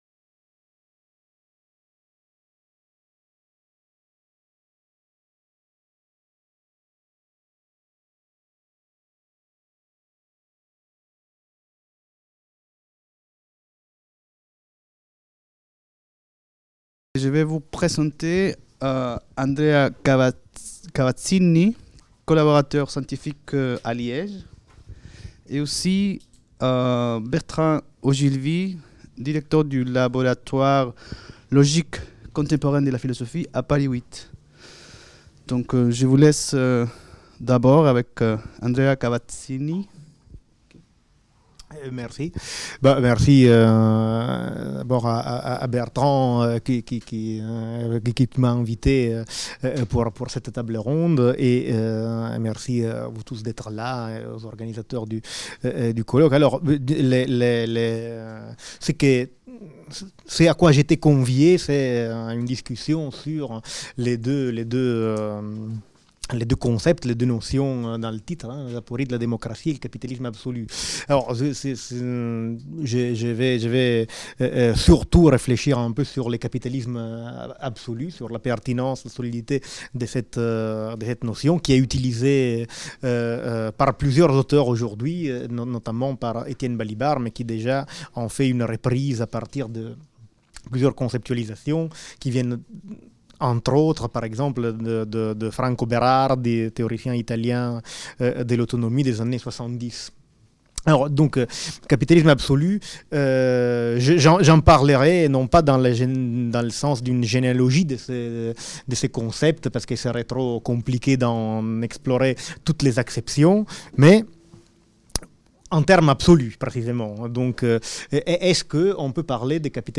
Apories de la démocratie | Table ronde internationale | Apories de la démocratie et capitalisme absolu | Canal U
1er Colloque international euro-latino-américain Du 29 novembre au 1er décembre 2017, s'est tenu à la Fondation Maison des sciences de l'homme le premier colloque international euro-latino-américain consacré aux apories de la démocratie.